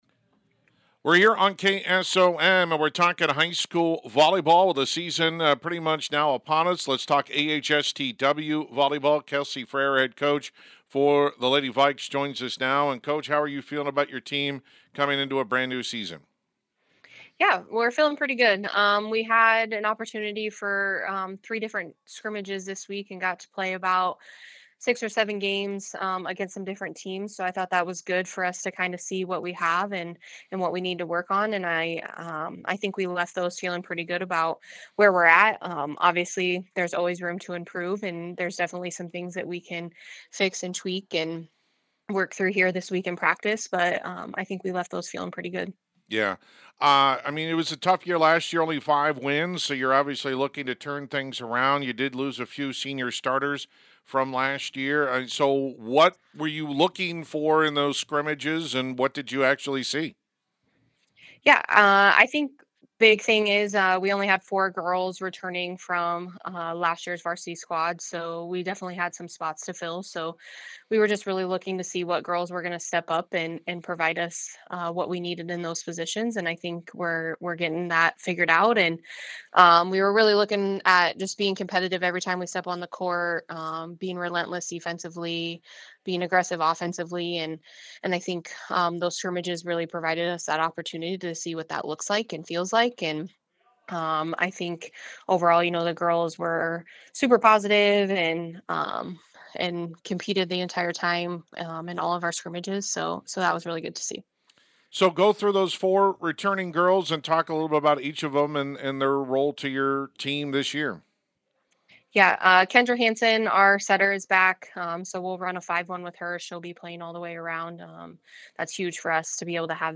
Complete Interview
ahstw-volleyball-8-26.mp3